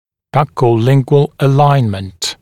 [ˌbʌkəu’lɪŋgwəl ə’laɪnmənt][ˌбакоу’лингуэл э’лайнмэнт]выравнивание в щечно-язычном направлении